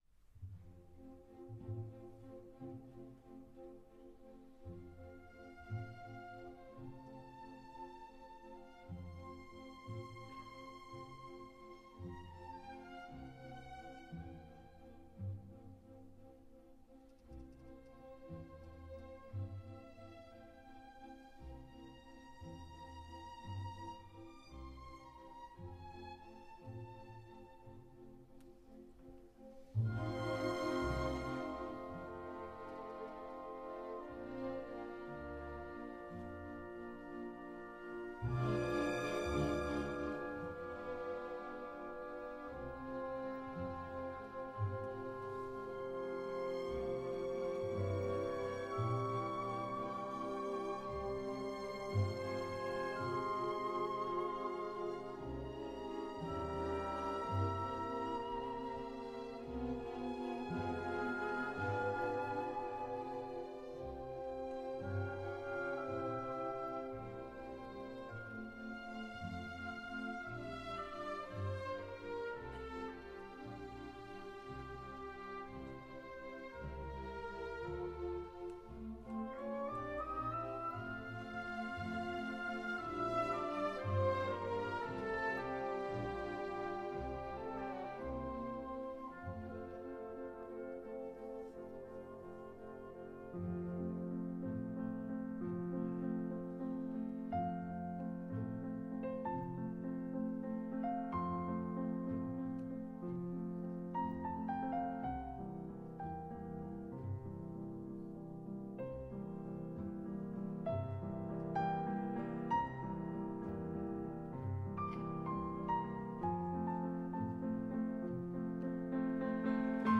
Wolfgang Amadues Mozart – Piano Concerto n° 21 “Elvira Madigan” – 2° Movimento: Andante